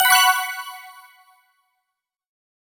SE_RESULT_SHOW_COIN_GET.wav